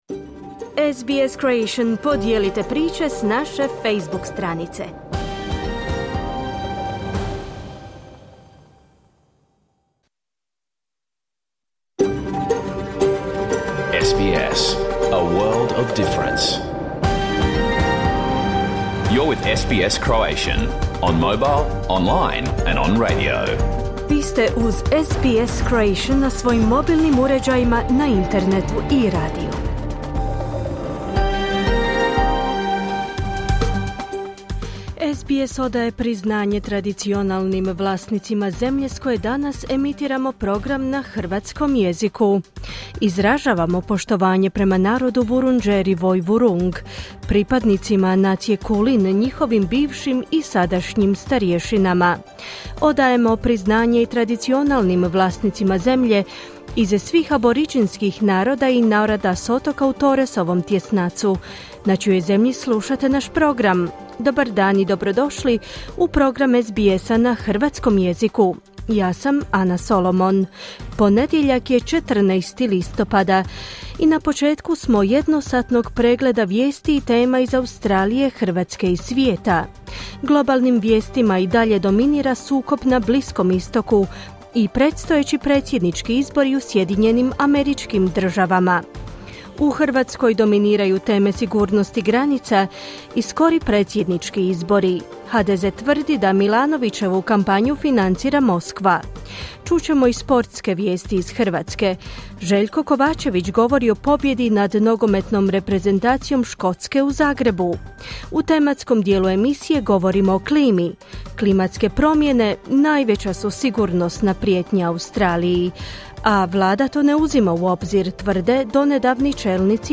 Vijesti i aktualnosti iz Australije, Hrvatske i svijeta, emitirane uživo na radiju SBS1 u ponedjeljak, 14. listopada u 11 sati, po istočnoaustralskom vremenu.